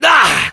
Heavy_painsharp02_fr.wav